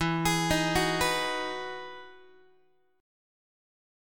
EM9 Chord
Listen to EM9 strummed